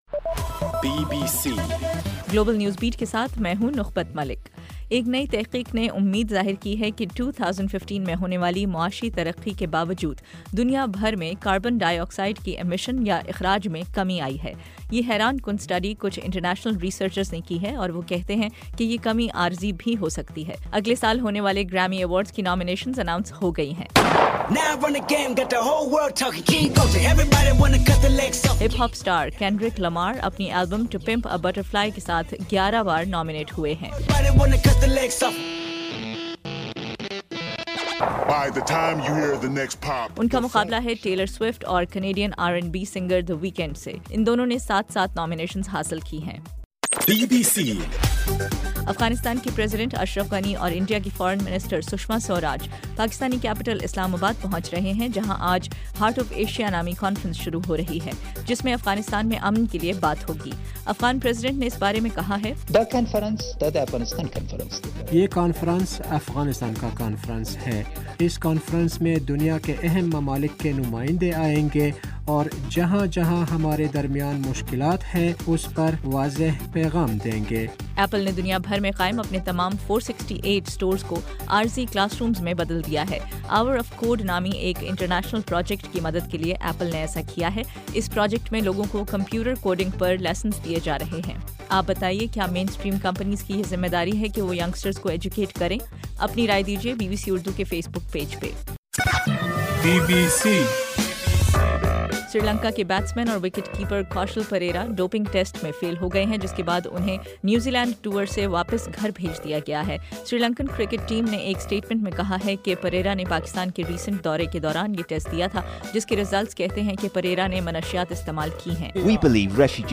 دسمبر 8: صبح 1 بجے کا گلوبل نیوز بیٹ بُلیٹن